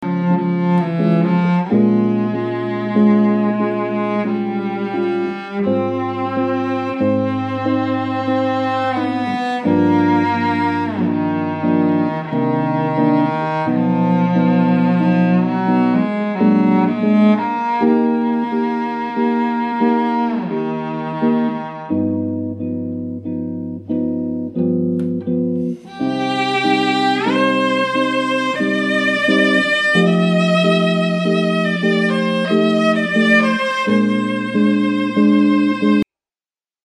violončelo
jazz kitara